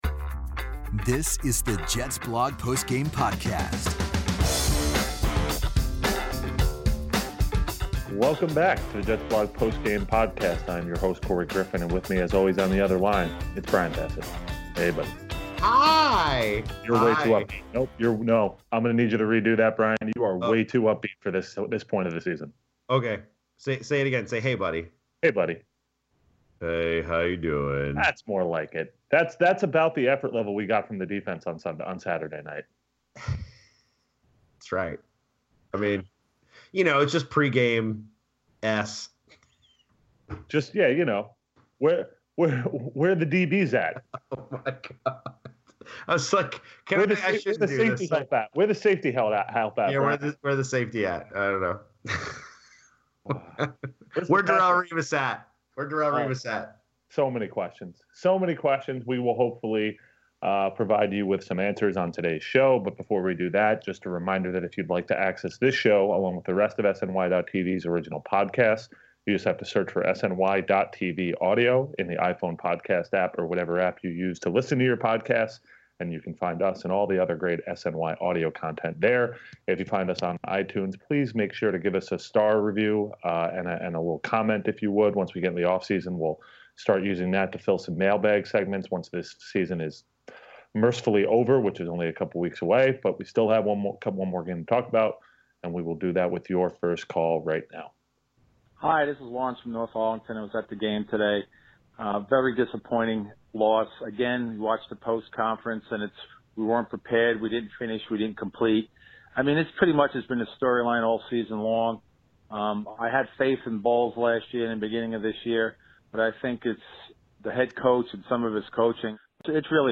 They take your calls covering the coaching, the quarterbacks, the secondary, and the ongoing search for hope after the Dolphins delivered the latest Jets loss in a lost 2016 season.